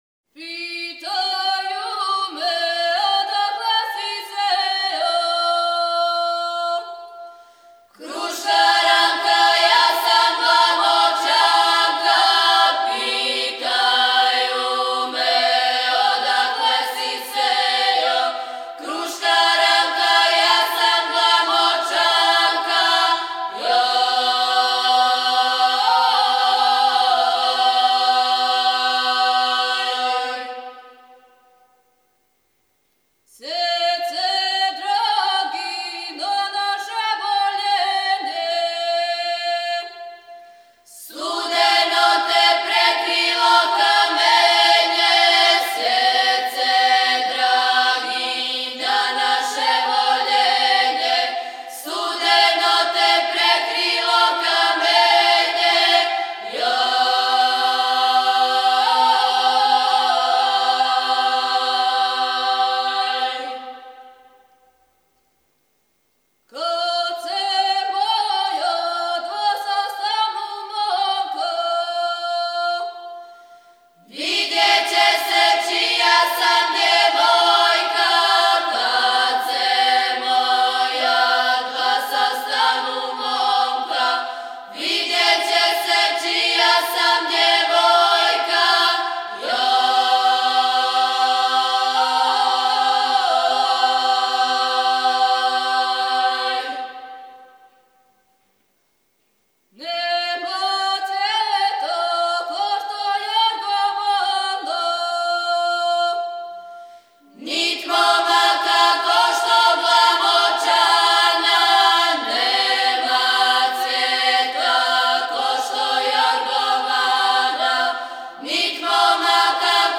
Снимци КУД "Др Младен Стојановић", Младеново - Девојачка и женска певачка група (7.1 MB, mp3) О извођачу Албум Уколико знате стихове ове песме, молимо Вас да нам их пошаљете .